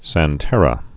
(săn-tĕrə, sän-)